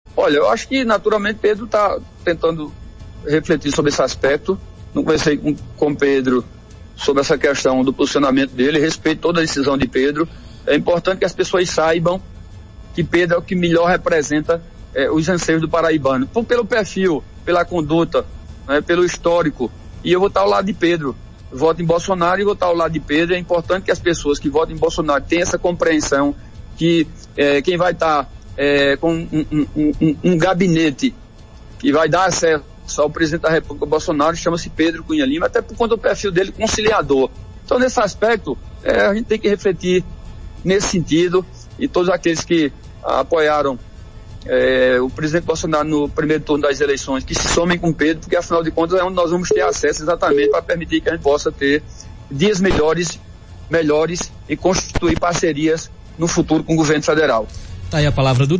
Um dos deputados federais mais bem votados nas eleições do último domingo (06), o ex-prefeito de Campina Grande, Romero Rodrigues (PSC), disse em entrevista nesta quinta-feira (06), que o candidato ao Governo pelo PSDB, Pedro Cunha Lima, ainda não bateu o martelo sobre o apoio para eleição presidencial.